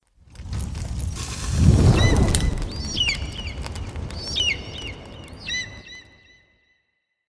Animals
Phoenix Babies is a free animals sound effect available for download in MP3 format.